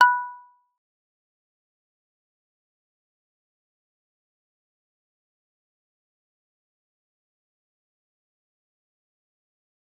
G_Kalimba-B5-f.wav